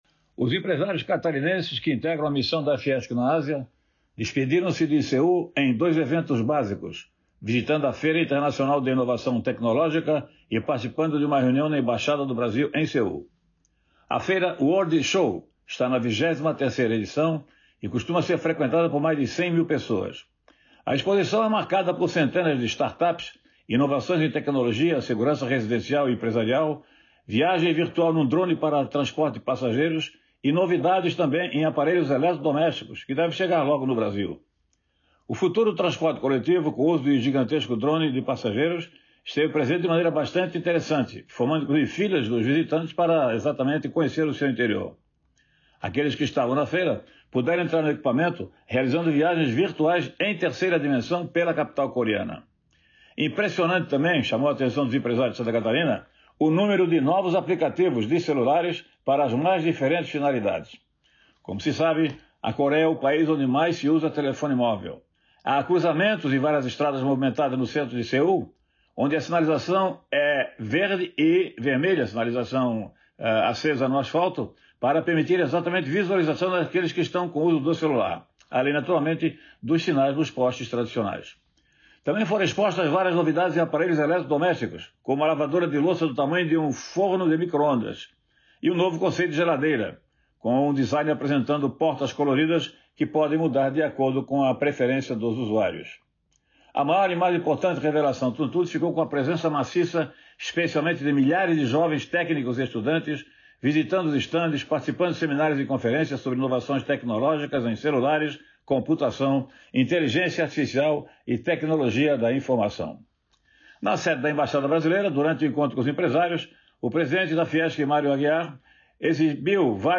Jornalista comenta as tendências e tecnologias de ponta que foram visitadas pela comitiva organizada pela Fiesc (Federação das Indústrias do Estado de Santa Catarina), em Seoul, na Coreia do Sul